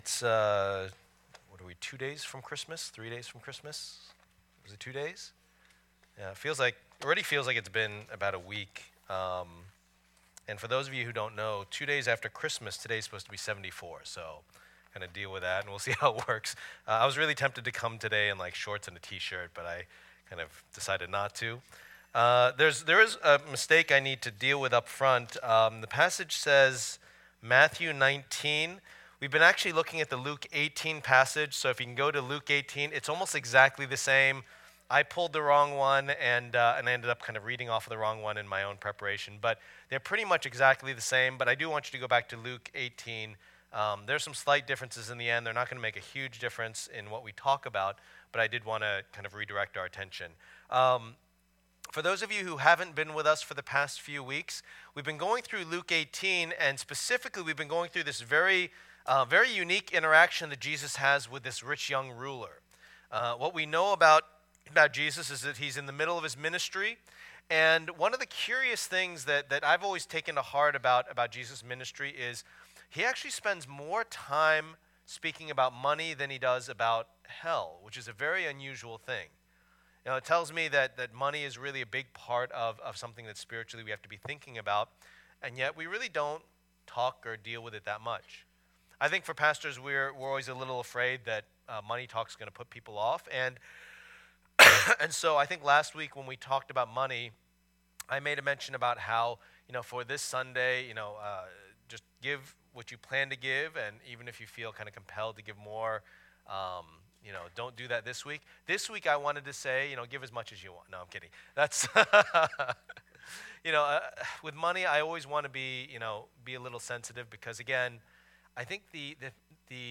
Passage: Matthew 19:16-30 Service Type: Lord's Day